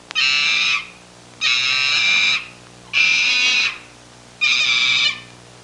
Gibbon Sound Effect
gibbon.mp3